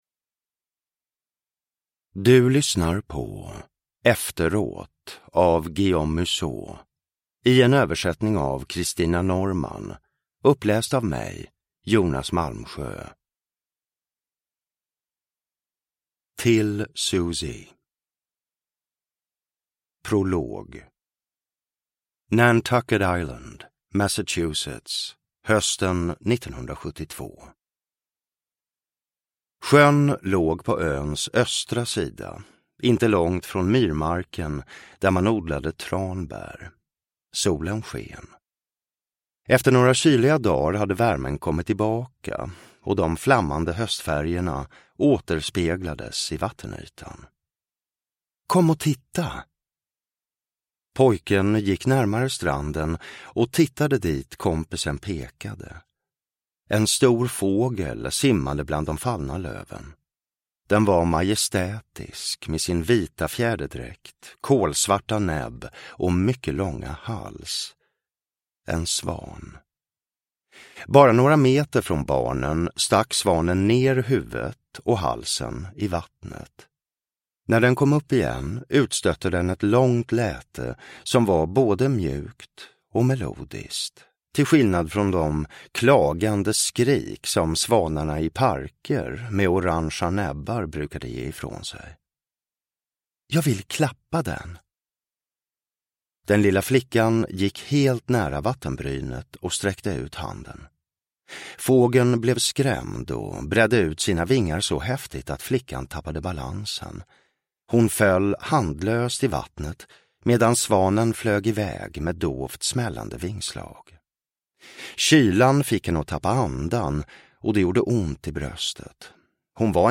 Uppläsare: Jonas Malmsjö
Ljudbok